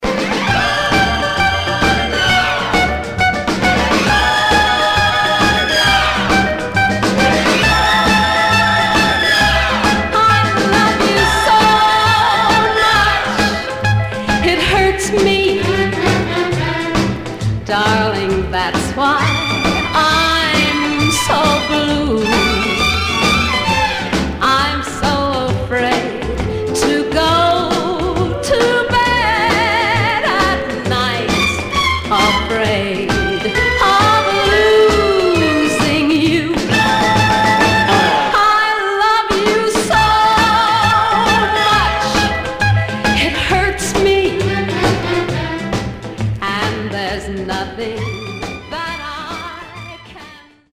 Teen (ie. Annette, Paul Anka) .........👈🏼 Condition